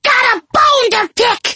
Kr4_fallen_ones_bone_flingers_taunt_4.mp3